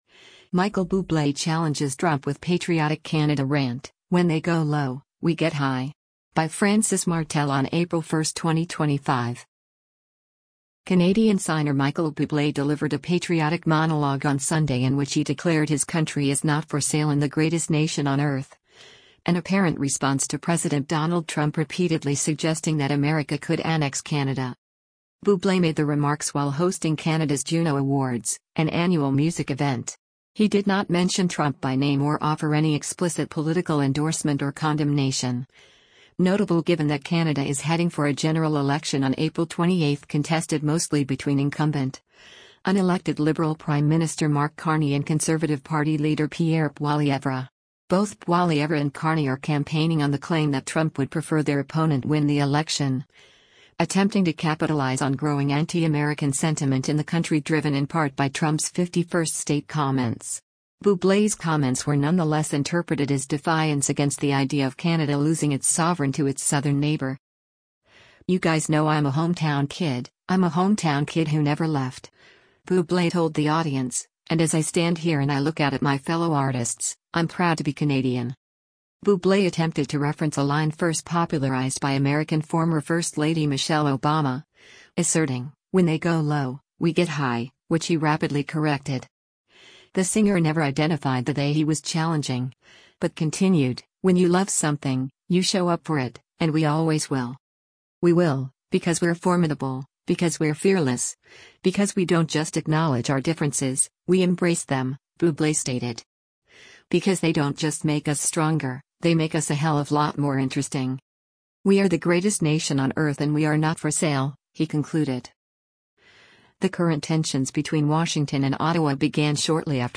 VANCOUVER, BRITISH COLUMBIA - MARCH 30: Michael Bublé speaks onstage during the 2025 JUNO
Bublé made the remarks while hosting Canada’s Juno Awards, an annual music event.